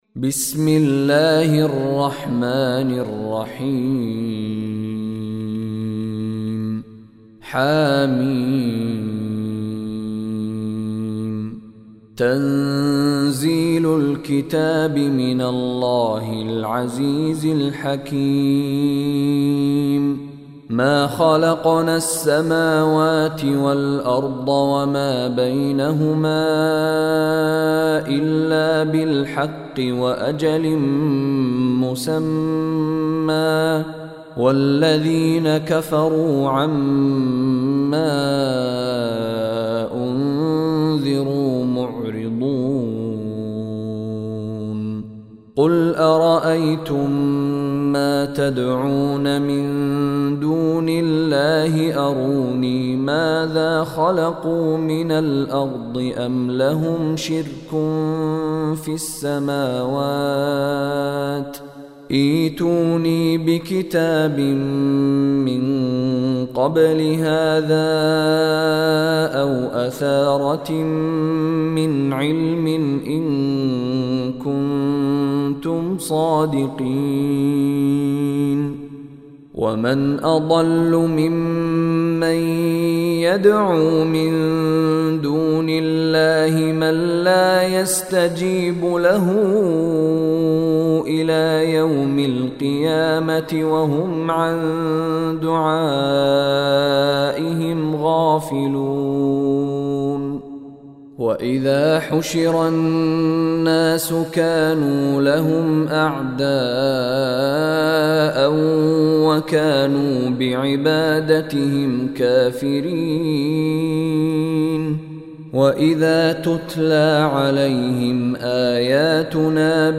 Surah Ahqaf Recitation by Mishary Rashid Alafasy
Surah Al-Ahqaf is 46 chapter / surah of Holy Quran. Listen online and download beautiful Quran tilawat / Recitation of Surah Al-Ahqaf in the voice of Sheikh Mishary Rashid Alafasy.